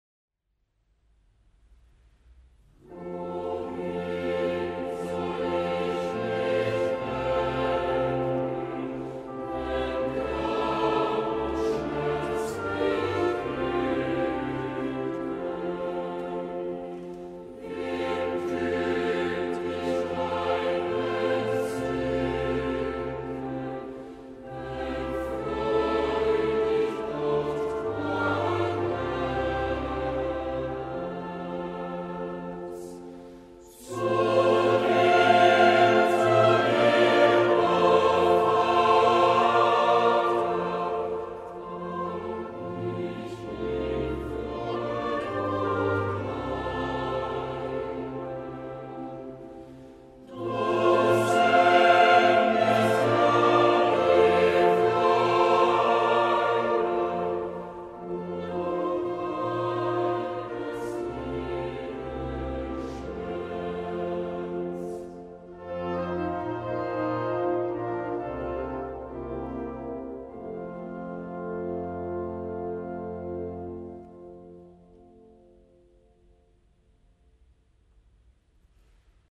1 Koor van St Augustinus in Wenen
Choir St Augustin Vienna - 1 - Zum Eingang.mp3